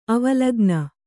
♪ avalagna